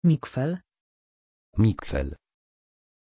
Aussprache von Miquel
pronunciation-miquel-pl.mp3